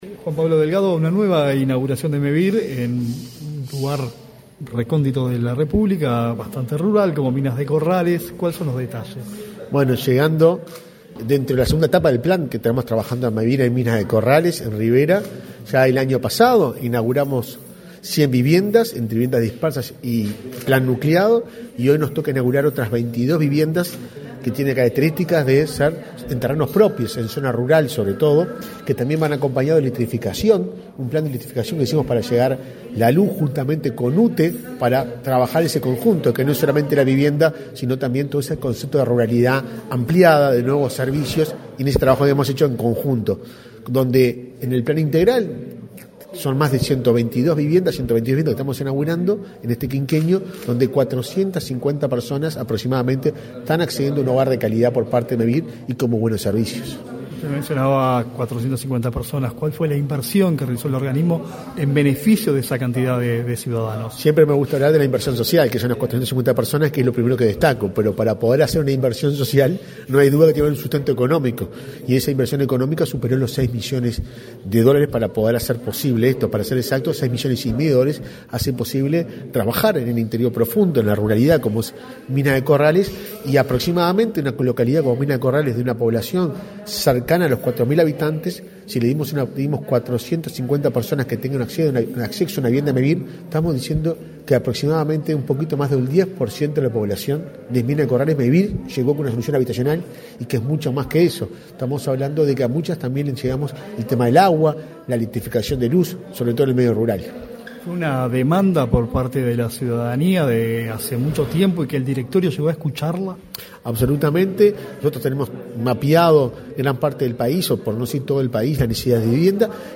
Entrevista con el presidente de Mevir, Juan Pablo Delgado
En la oportunidad, el presidente del organismo, Juan Pablo Delgado, realizó declaraciones a Comunicación Presidencial.